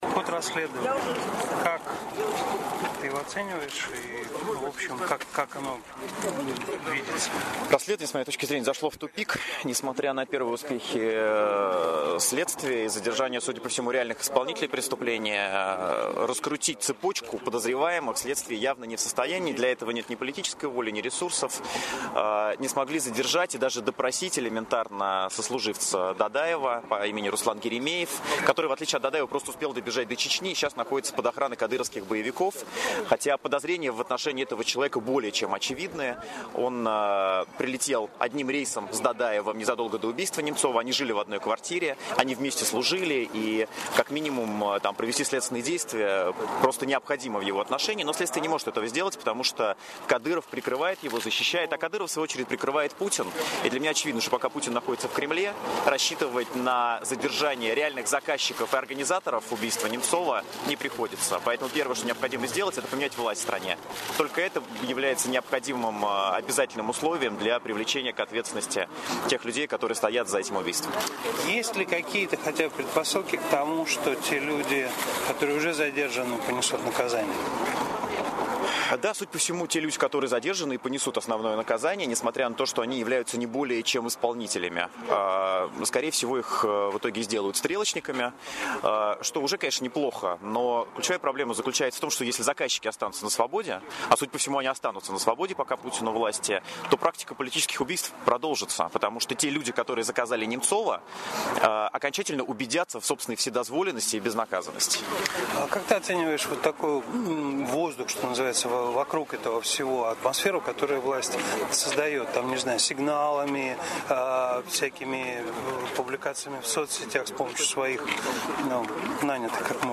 На Большом Москворецком мосту около Кремля – там, где Борис Немцов был убит – собрались сотни людей, чтобы почтить память погибшего оппозиционера.